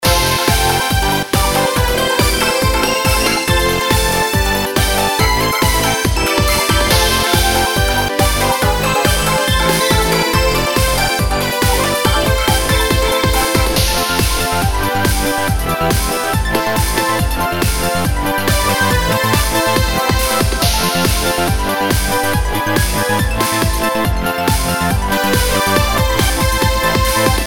シンセ音作りサンプル プラックまでのまとめ
シンセ音作りサンプル　プラックまでのまとめ.mp3